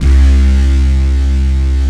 DISTBASSC2-R.wav